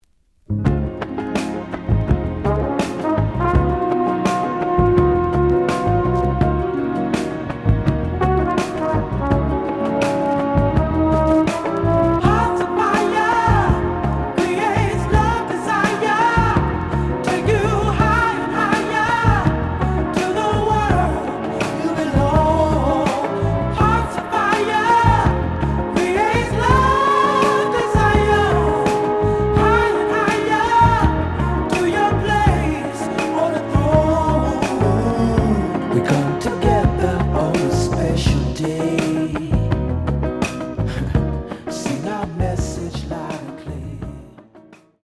The audio sample is recorded from the actual item.
●Genre: Funk, 70's Funk
Looks good, but slight noise on beginning of A side.